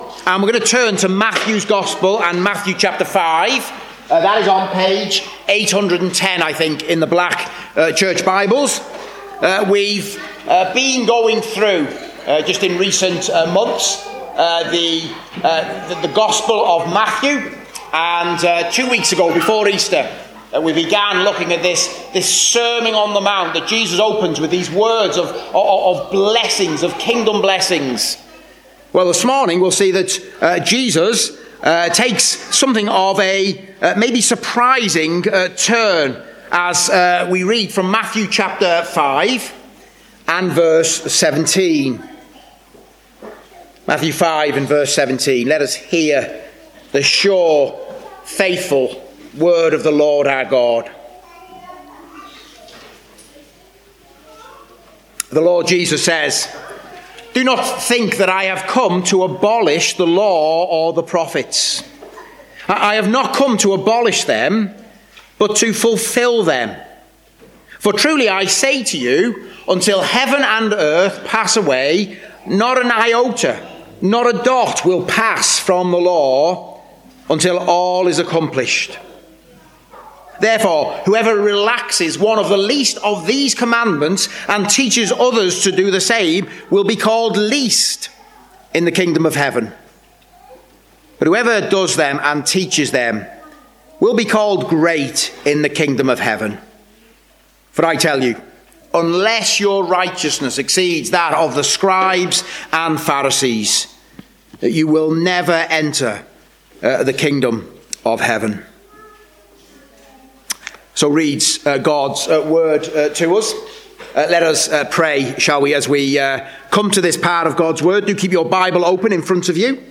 Service Type: Preaching